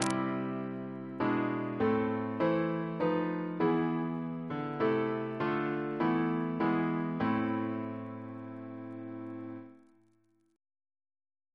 CCP: Chant sampler
Single chant in A minor Composer: Sir H. Walford Davies (1869-1941), Organist of the Temple Church and St. George's, Windsor Reference psalters: ACB: 173